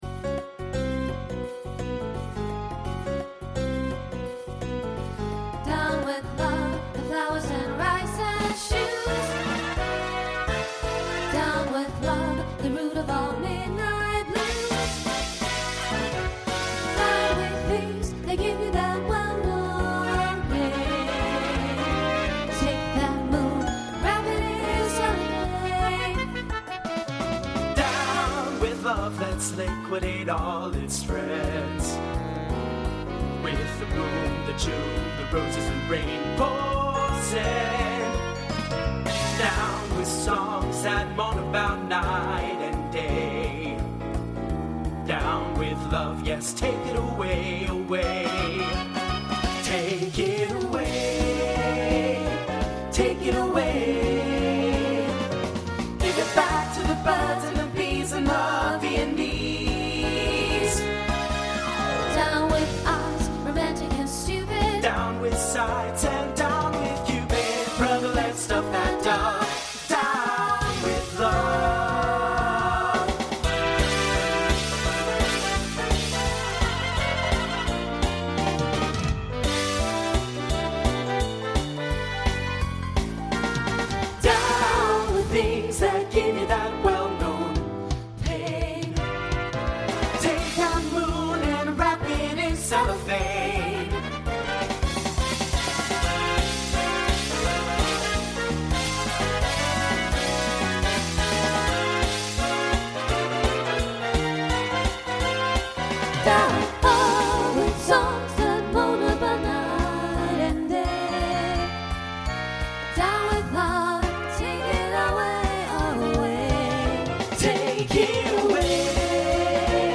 Voicing SATB Instrumental combo Genre Swing/Jazz